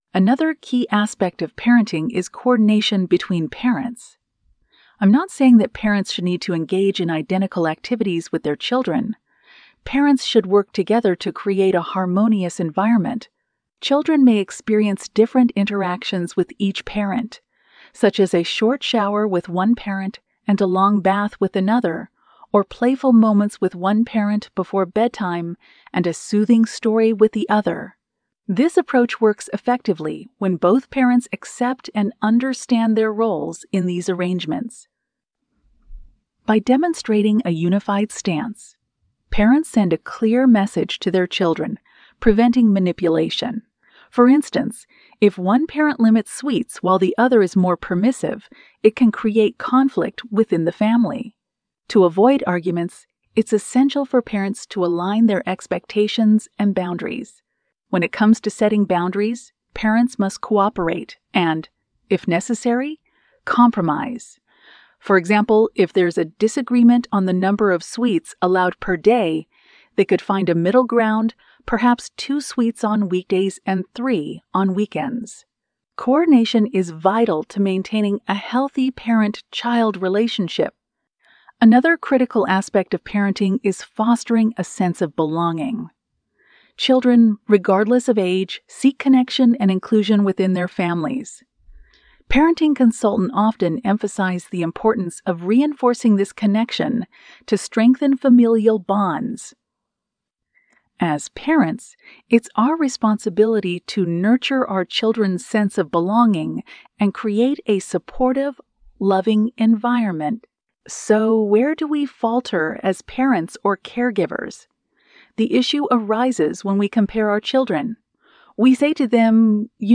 Lecture 4: What Parents Should Know-Part2